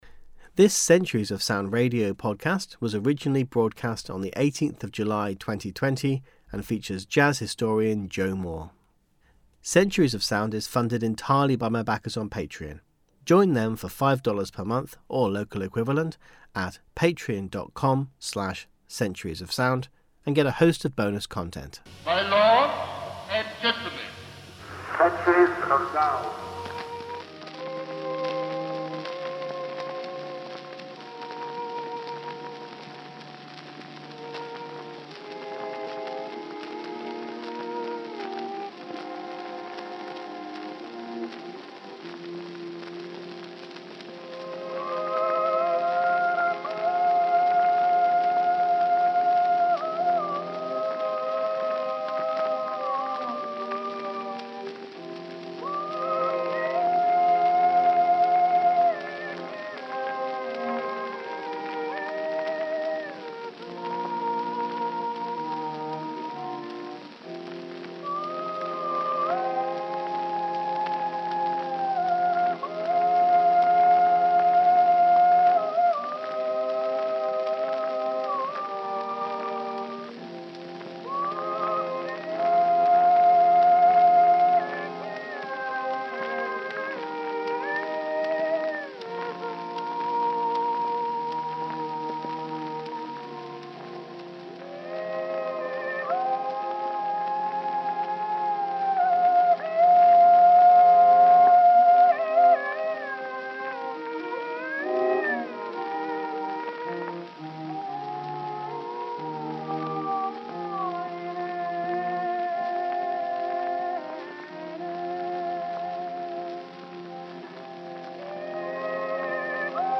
A genuinely astonishing time for music, brought to life with contemporary archive sounds.